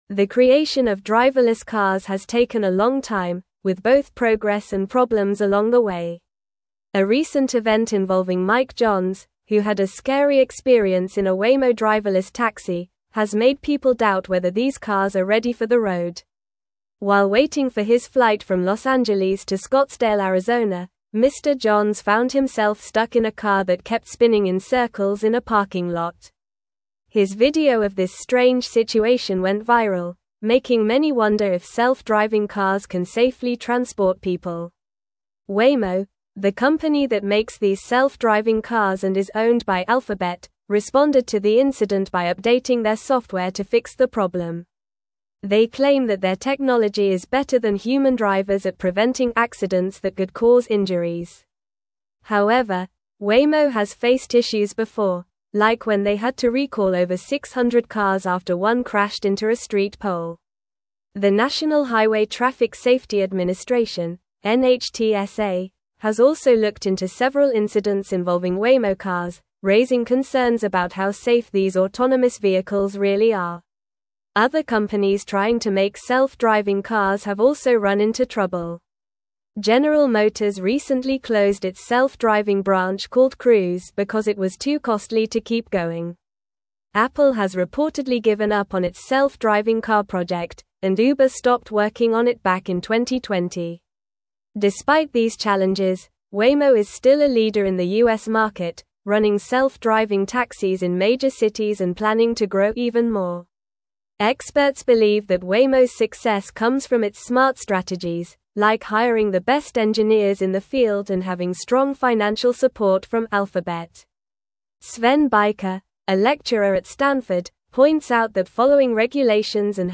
Normal
English-Newsroom-Upper-Intermediate-NORMAL-Reading-Challenges-Persist-in-the-Adoption-of-Driverless-Vehicles.mp3